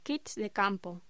Locución: Kits de campo